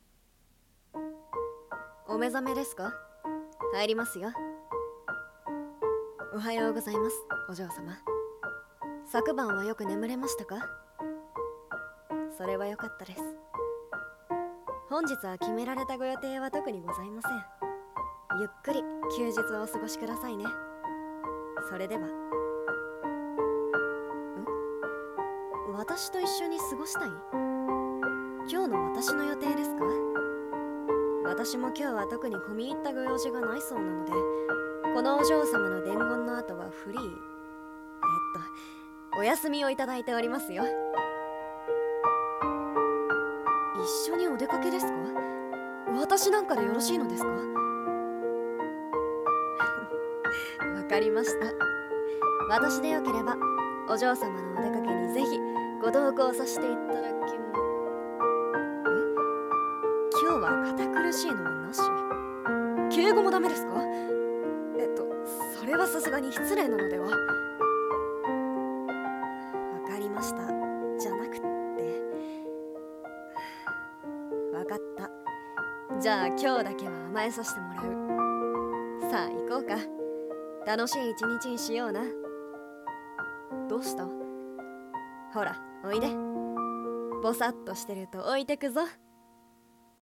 【一人声劇】とある執事の特別な朝